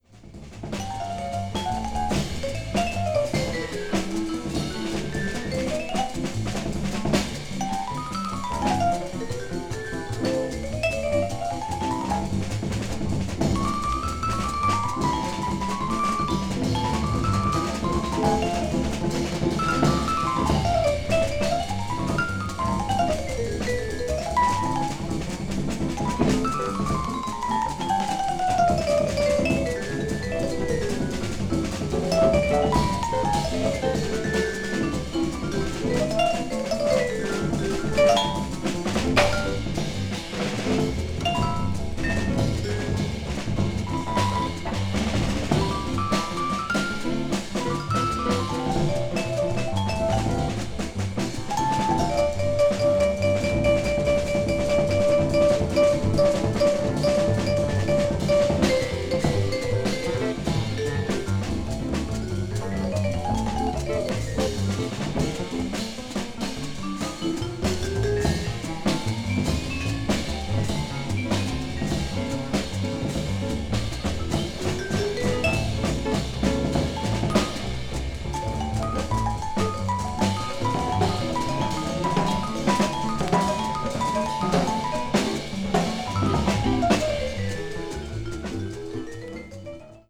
alto saxophonist
vibraphone
guitar
bass
drums
a long-form session